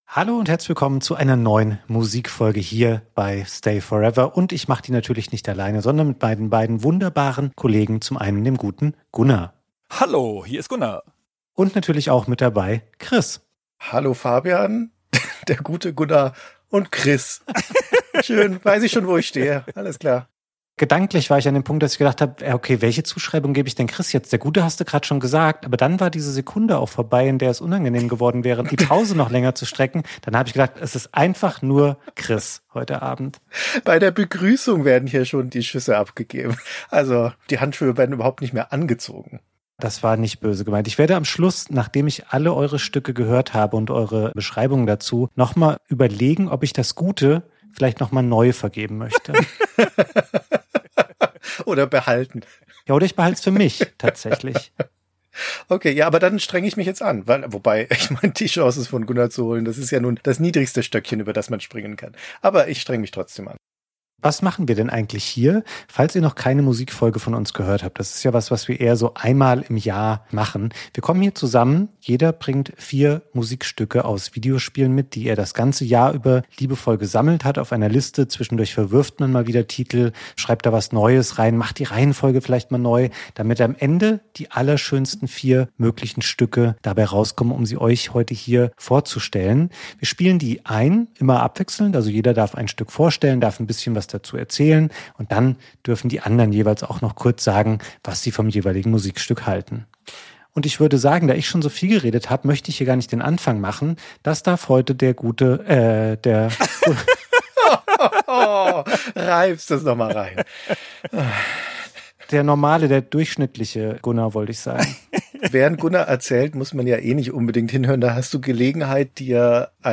Die Stücke werden vom jeweiligen Aussucher angespielt und vorgestellt, die anderen beiden kommentieren mehr oder minder wohlwollend.